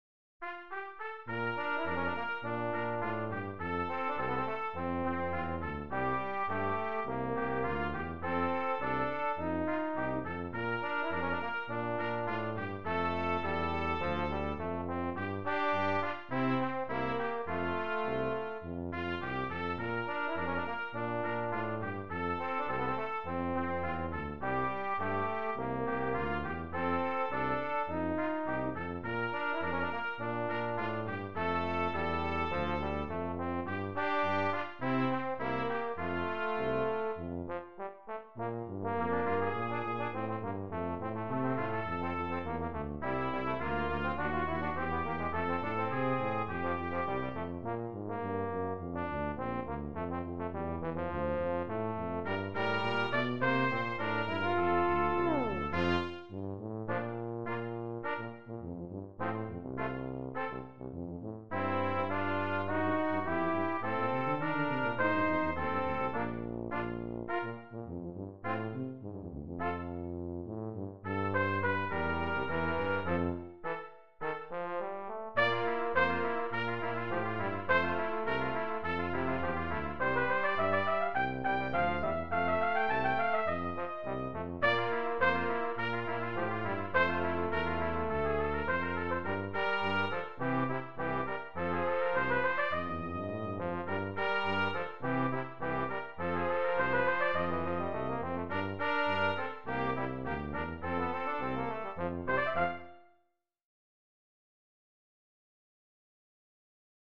Brass Trio TTT
Traditional American hymn melody